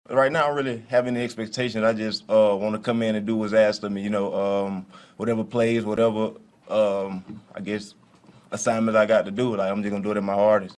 Chiefs wide receiver Kadarius Toney says that he’s ready to do whatever the Chiefs ask him to do in his first game with Kansas City.